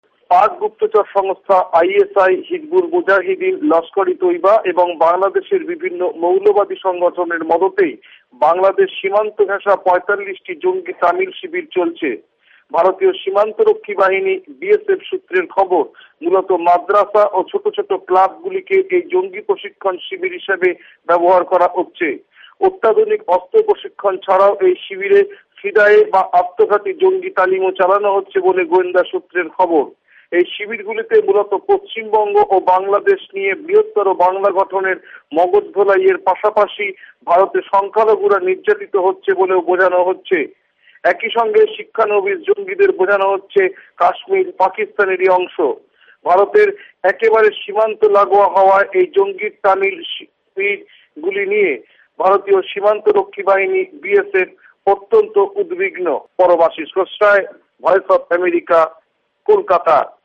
ভয়েস অফ এ্যামেরিকার কলকাতা সংবাদদাতাদের রিপোর্ট